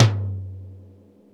TOM XC.TOM06.wav